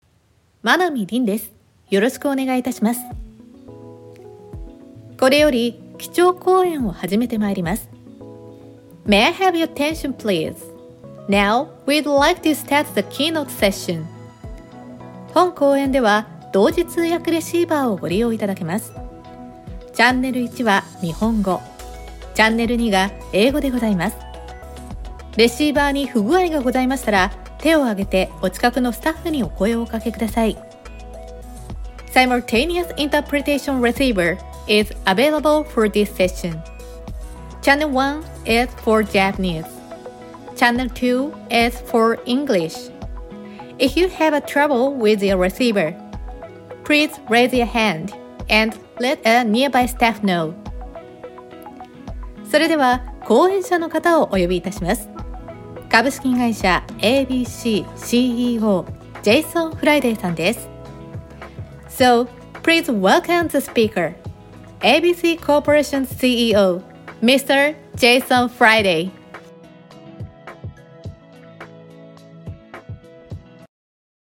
ナレーター｜MC